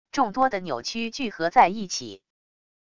众多的扭曲聚合在一起wav音频